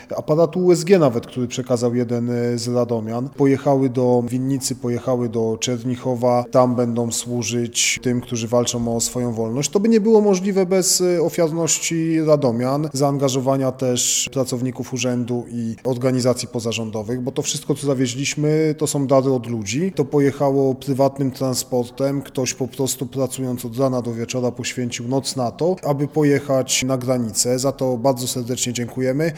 O szczegółach mówi Mateusz Tyczyński wiceprezydent Radomia: